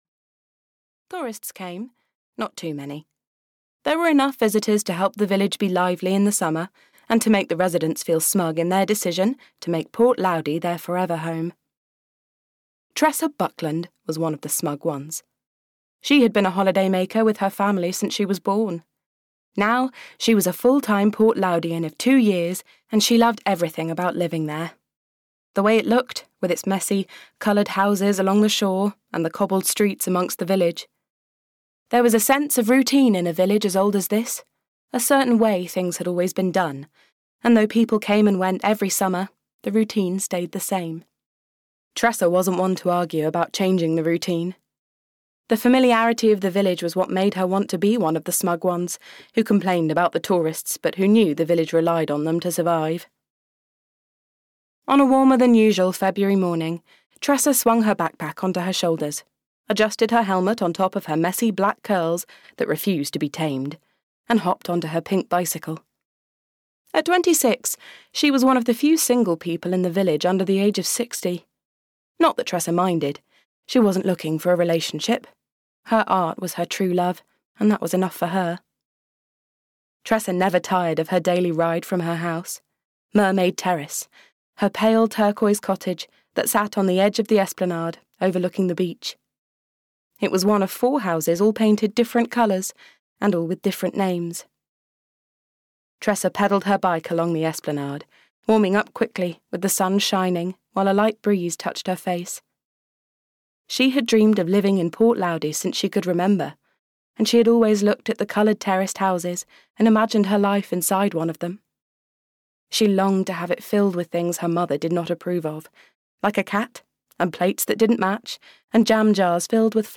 Finding Love at Mermaid Terrace (EN) audiokniha
Ukázka z knihy